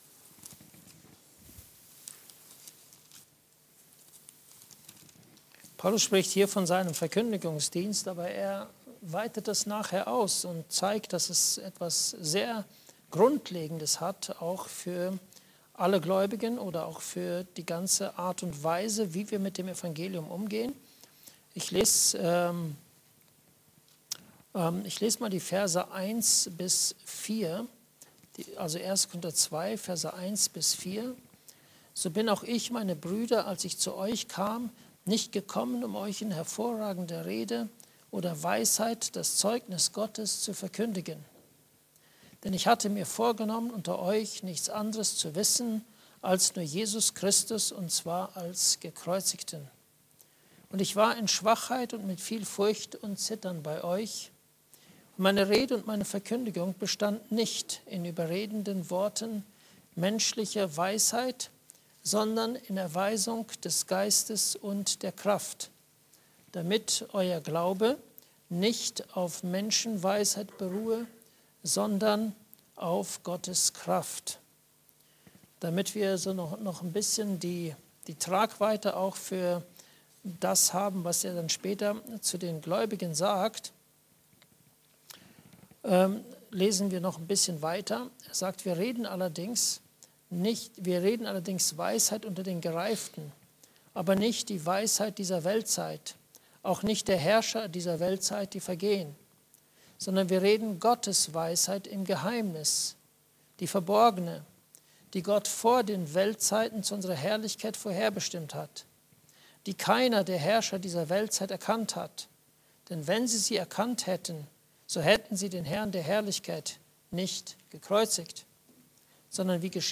Gebet in der Abhängigkeit des Heiligen Geistes (Andacht Gebetsstunde)